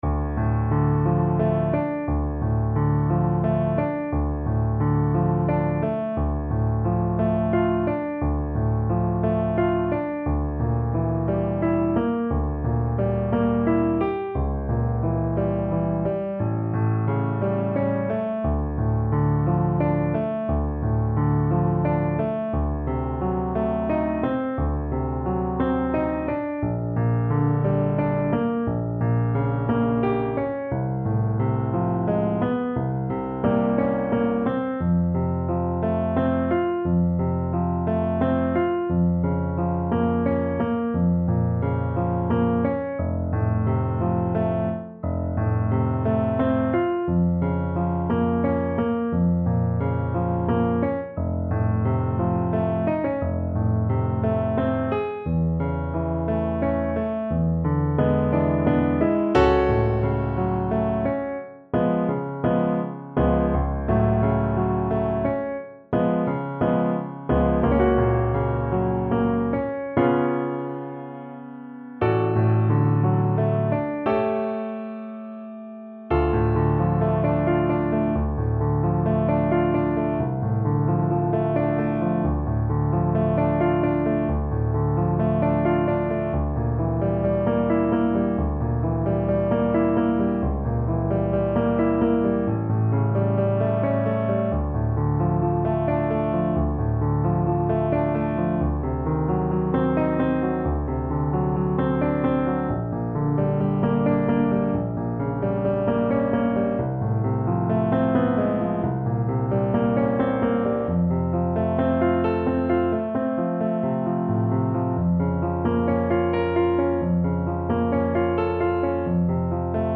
Play (or use space bar on your keyboard) Pause Music Playalong - Piano Accompaniment Playalong Band Accompaniment not yet available transpose reset tempo print settings full screen
D minor (Sounding Pitch) E minor (Trumpet in Bb) (View more D minor Music for Trumpet )
3/4 (View more 3/4 Music)
~ = 88 Malinconico espressivo
Classical (View more Classical Trumpet Music)